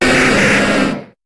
Cri de Rhinocorne dans Pokémon HOME.